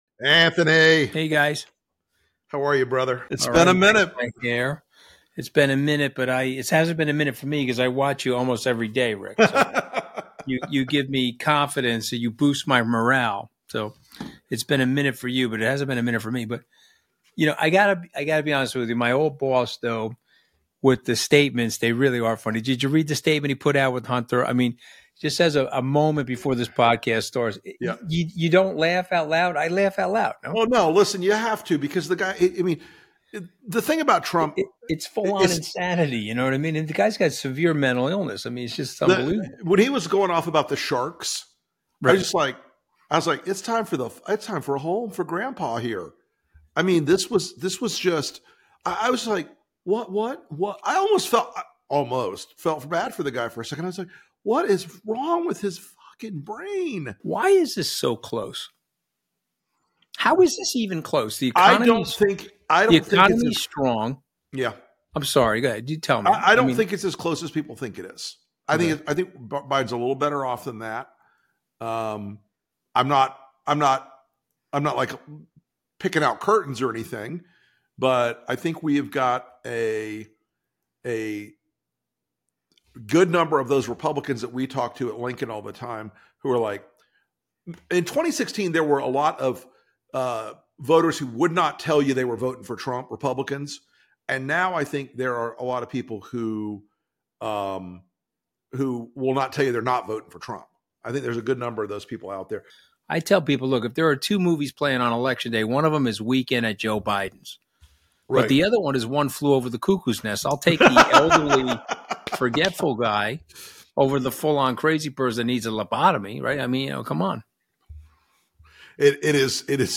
Never one to mince words, Anthony Scaramucci joins host Rick Wilson on the LP podcast. The Mooch says being fired from Trump's White House was the worst and, surprisingly, the BEST day of his life. In this episode Rick and Anthony discuss the humble genesis of his unorthodox career, the insanity of the current news cycle, and what's at stake for America in this upcoming election.